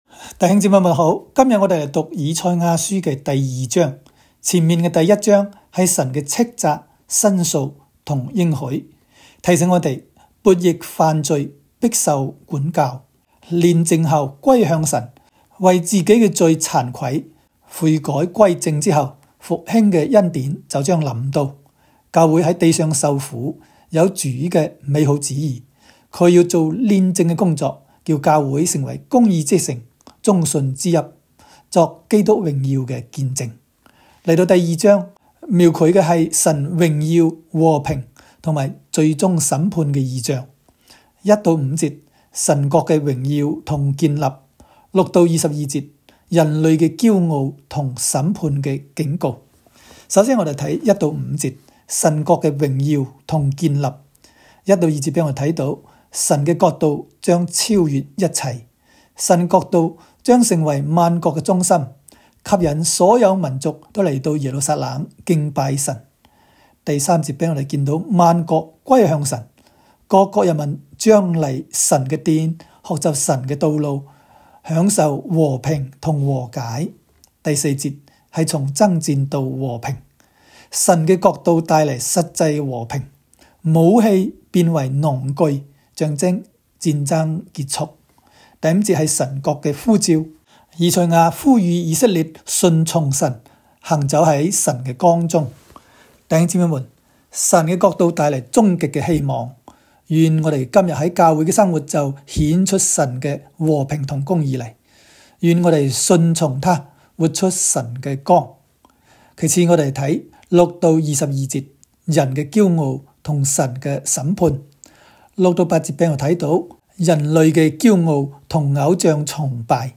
赛02（讲解-粤）.m4a